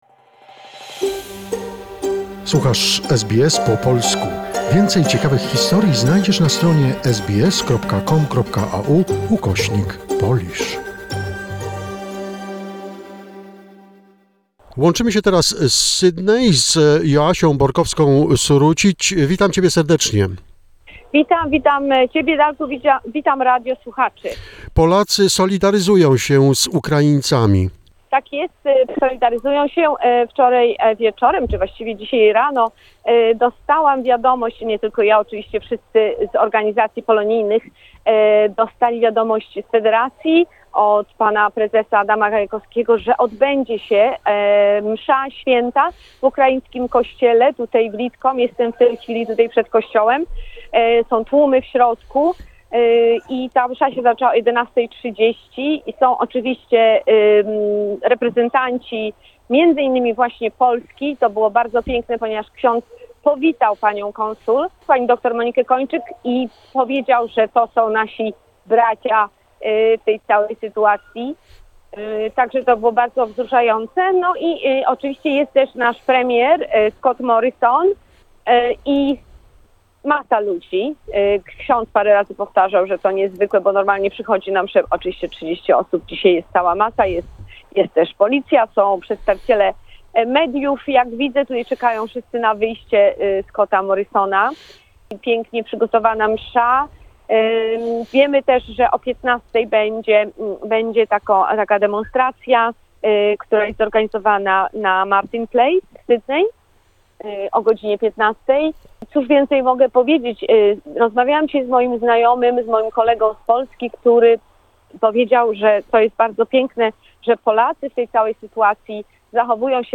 In Sydney, a Vigil Service was held at the St Andrews Ukrainian Catholic Church (Lidcombe) with the participation of the Prime Minister of Australia, Scott Morrison. The Consul General of the Republic of Poland in Sydney, Dr. Monika Kończyk was also present.